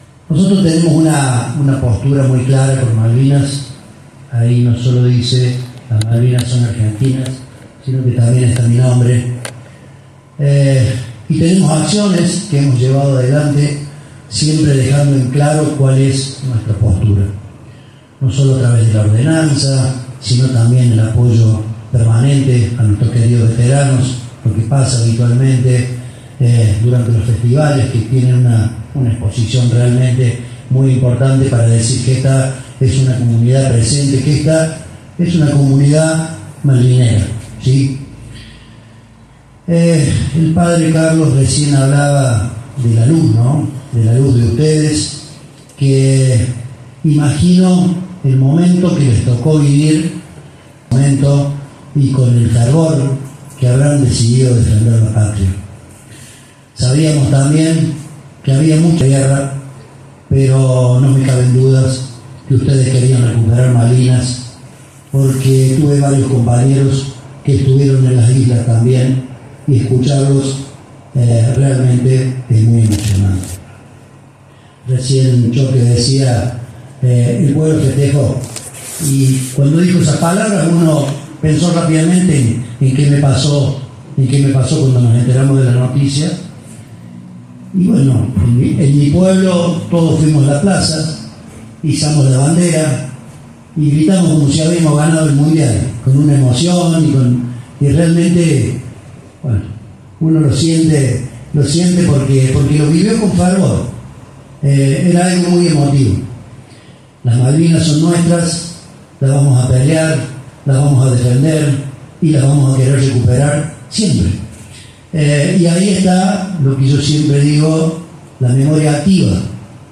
La vigilia, conocida como “Noche de Gloria”, se realizó el miércoles por la noche en el salón Cura Monguillot.
En esa línea, el intendente Raúl Cardinali de la ciudad hizo hincapié en la importancia de sostener el recuerdo en el tiempo y transmitirlo a las nuevas generaciones: